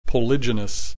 click this icon to hear the preceding term pronounced household are weak is somewhat of an ethnocentric projection that does not fit the reality in all societies.